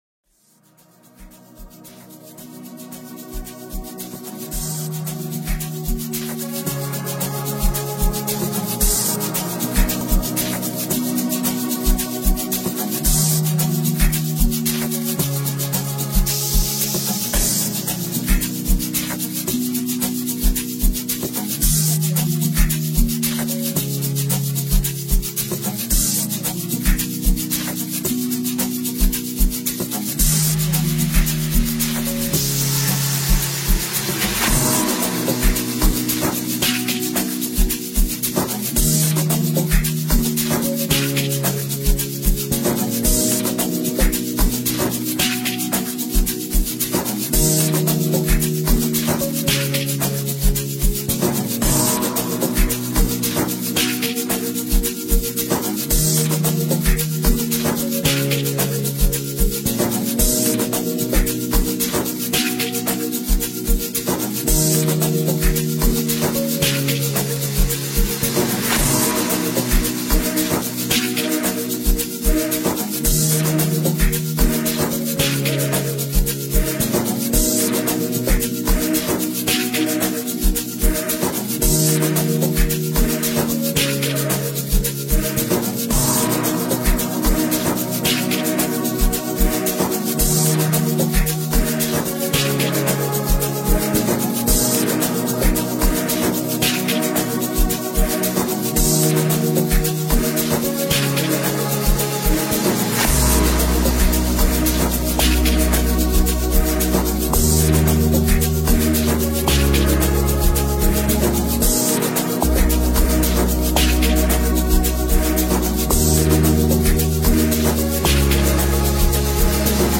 complete piano production